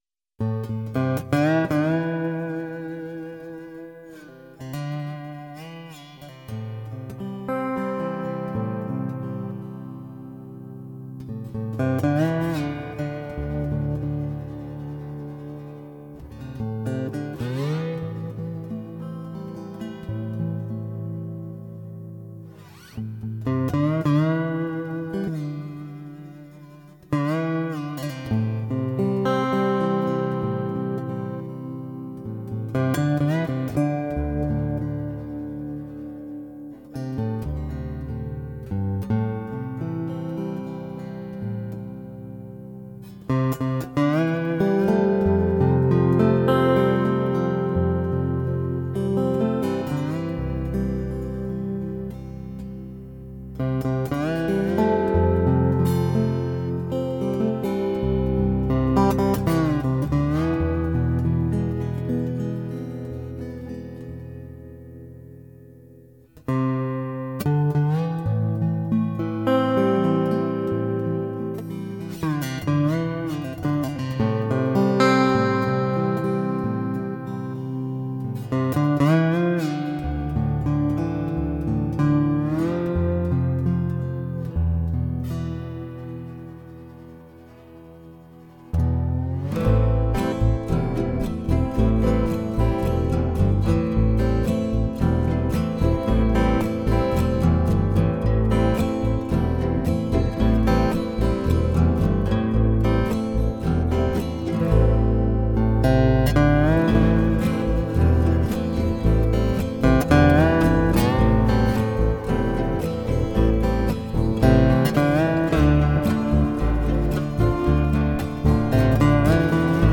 using what he termed “Thrift Store Guitars.”